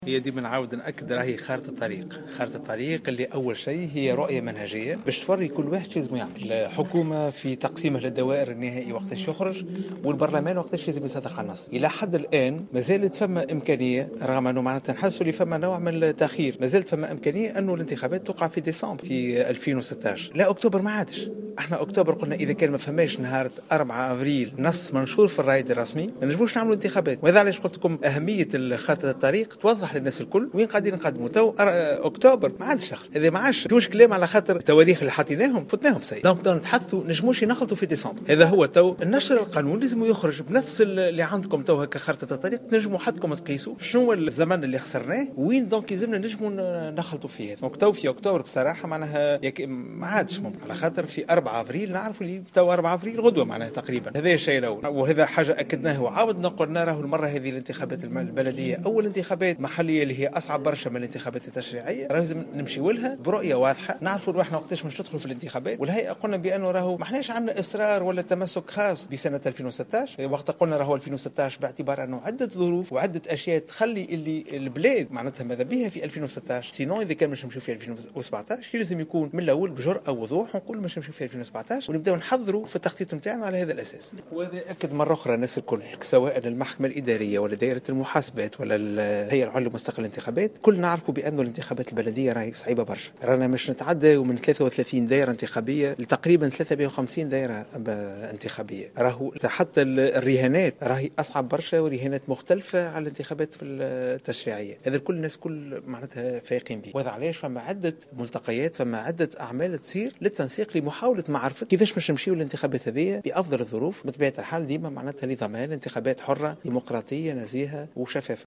قال رئيس الهيئة العليا المستقلة للانتخابات شفيق صرصار في تصريح للجوهرة أف أم اليوم الخميس 24 مارس 2016 على هامش ندوة صحفية نظمتها الهيئة المستقلة للاتصال السمعي البصري إنه لا يمكن إجراء الانتخابات البلدية قبل ديسمبر 2016.